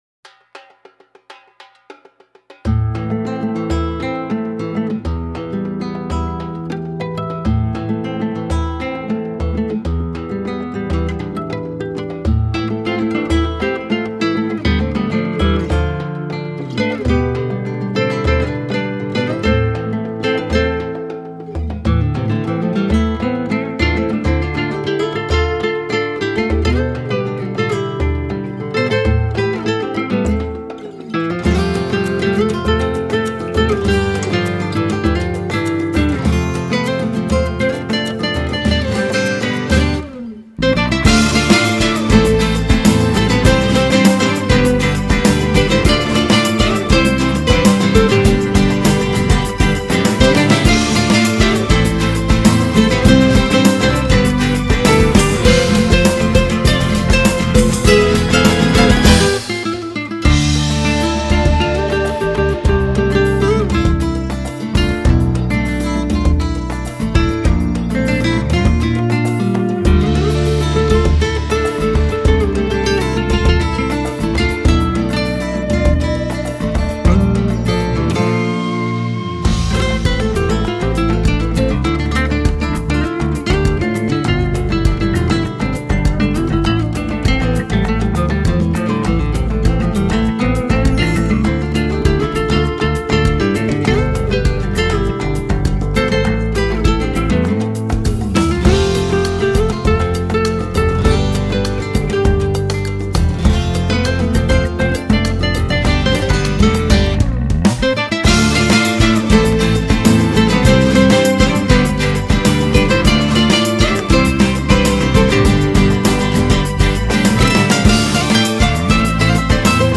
Upbeat and jazzy
a spicy instrumental that will get you on your feet.